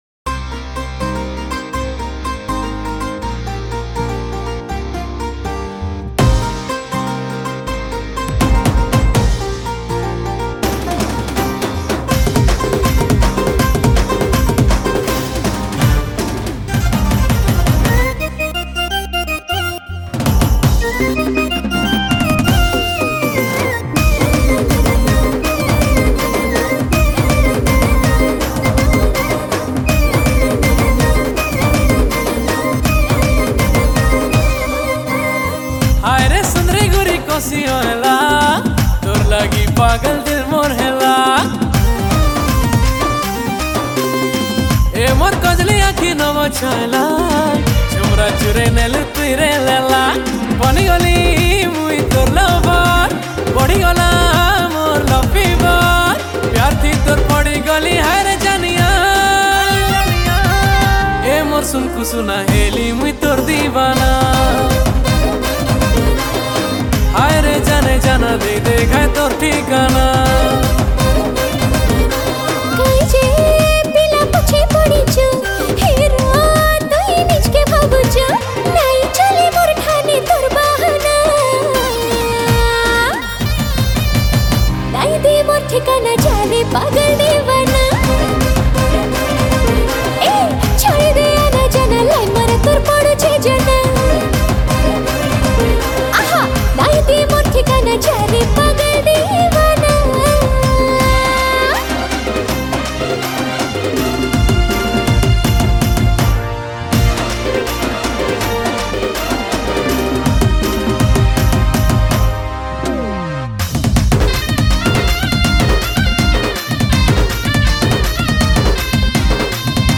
New Sambalpuri Song 2025